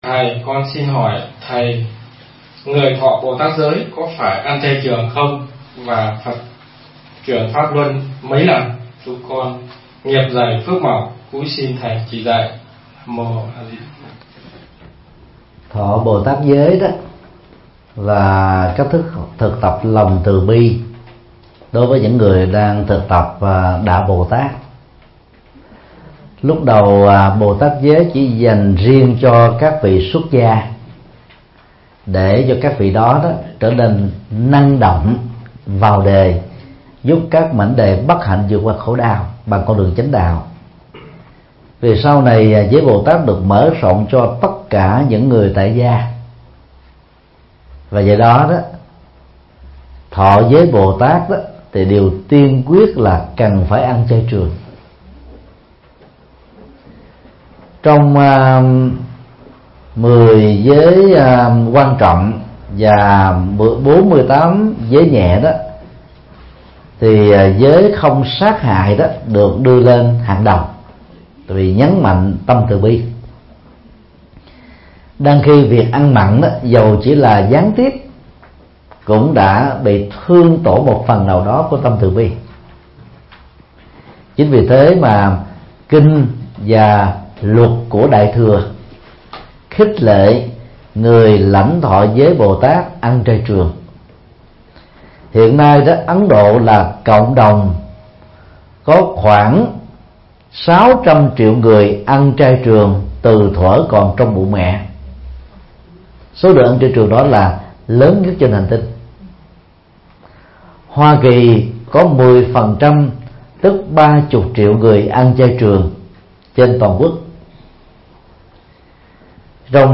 Vấn đáp: Bồ tát giới và ăn chay trường – Thích Nhật Từ